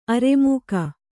♪ aremūka